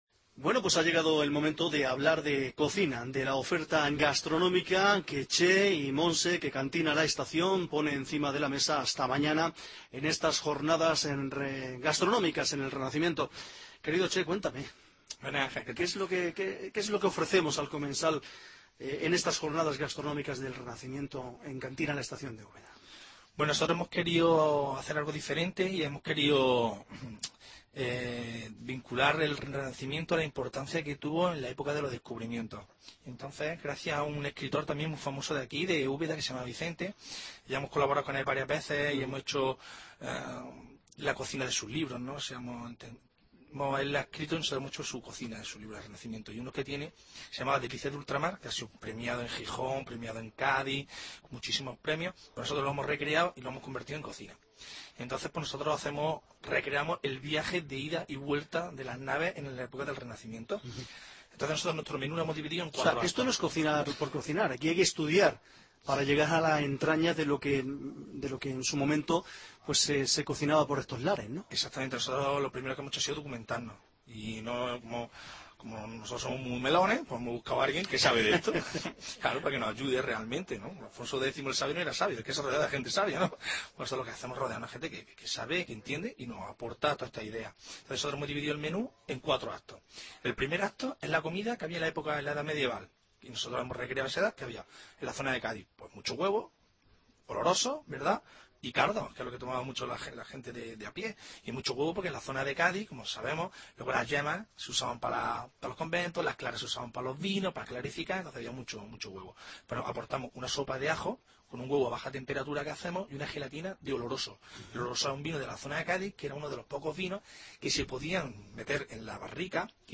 ENTTREVISTA CON CANTINA LA ESTACIÓN DE ÚBEDA